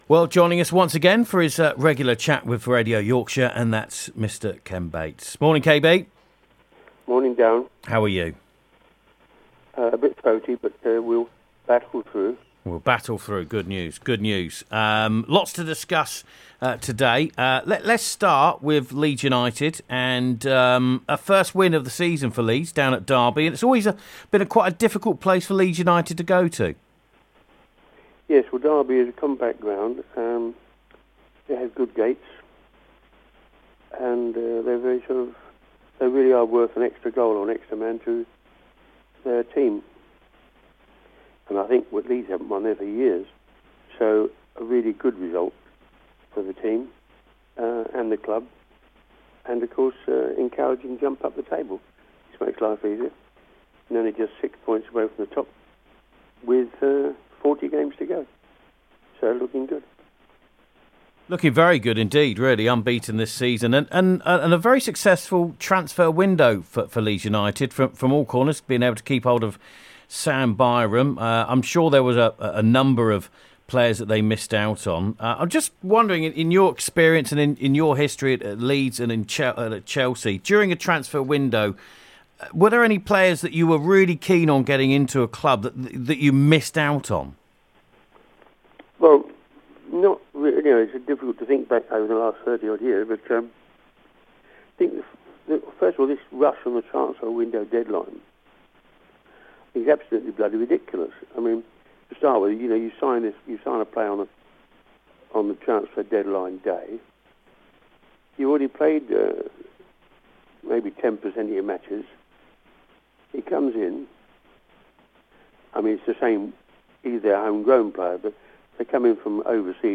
KEN BATES INTERVIEW